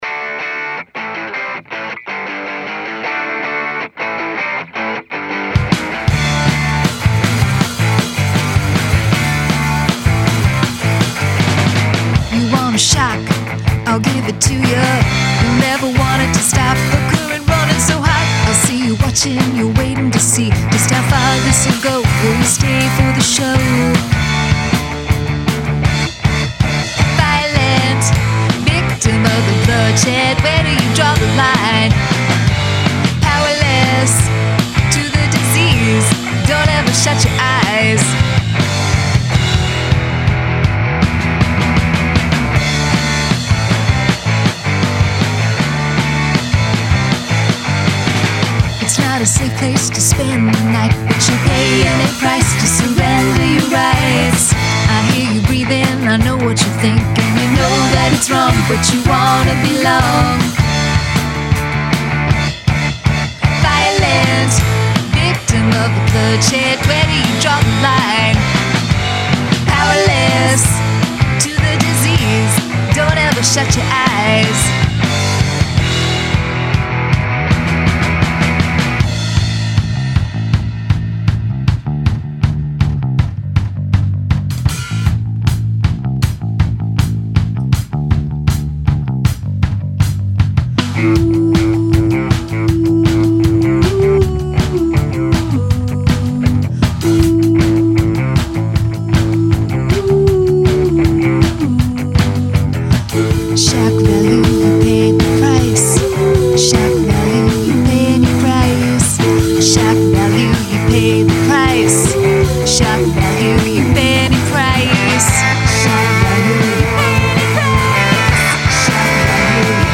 I really like the panned intro to this song.
and the dry lead vocal is so immediate.